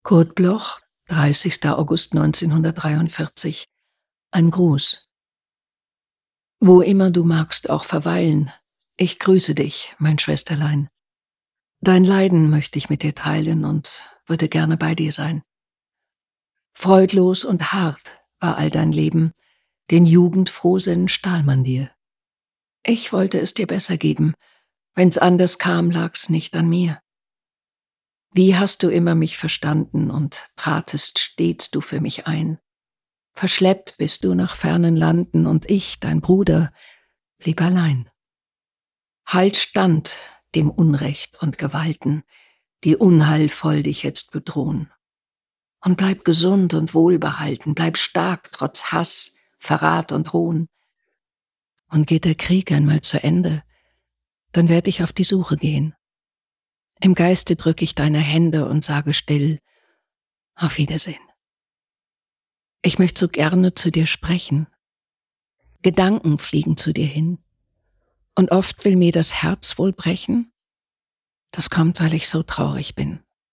Iris Berben (geboren in 1950) is actrice en stemactrice.